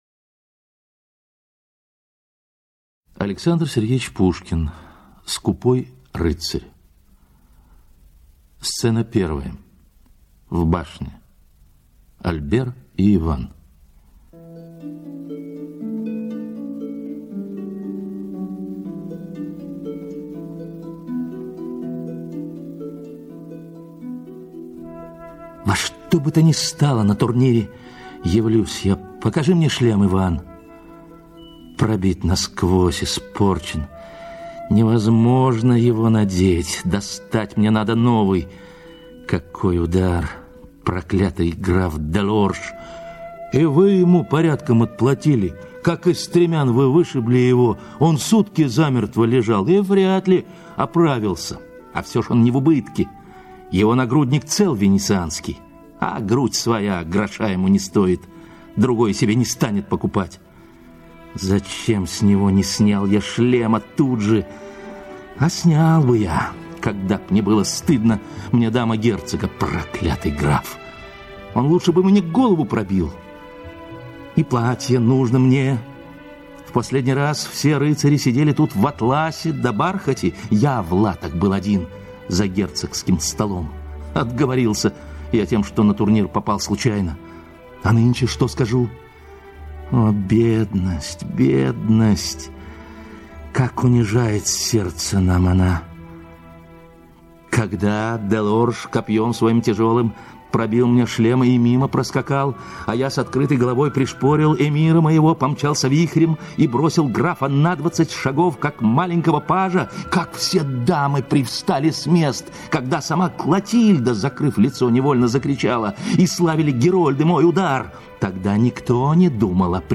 Аудиокнига Скупой рыцарь
Качество озвучивания весьма высокое.